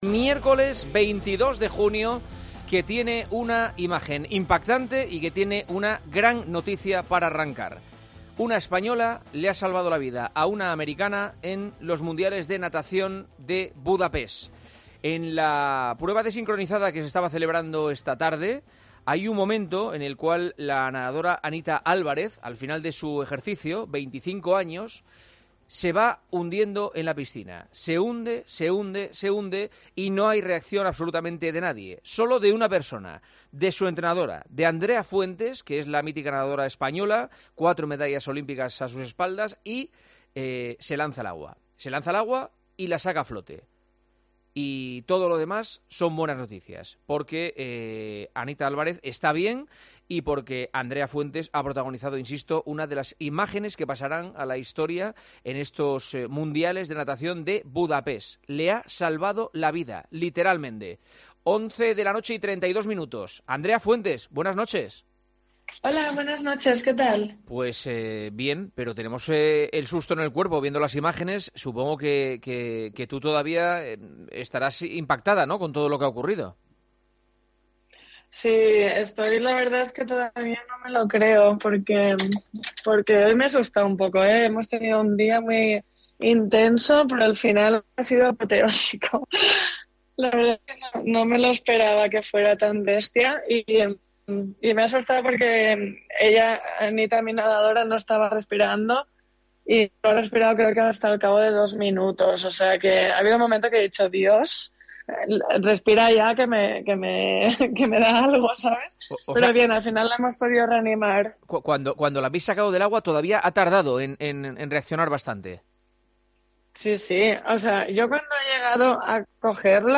Andrea Fuentes, en El Partidazo de COPE: "Todavía no me lo creo, Anita ha estado dos minutos sin respirar"